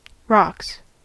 Ääntäminen
IPA : /rɒks/